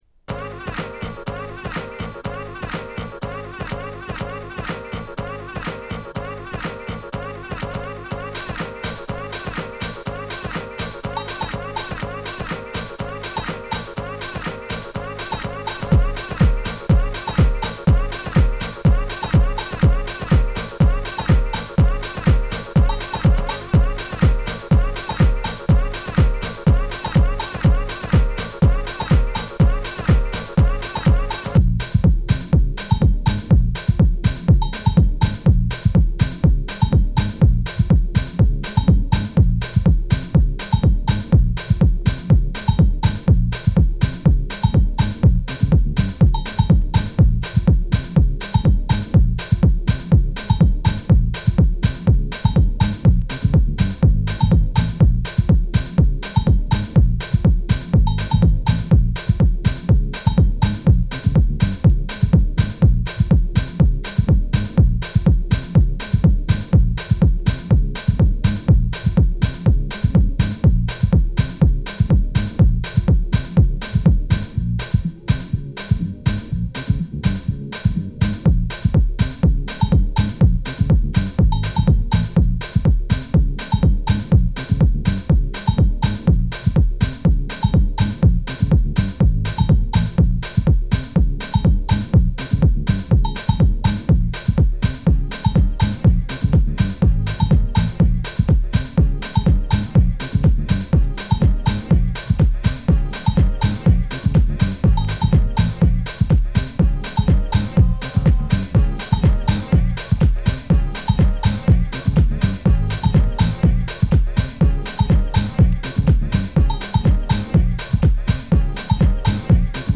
ピッチダウンしたミッド・ブレイクに、心地よく浮遊する鍵盤が煌めくメロー・フュージョン・グルーヴ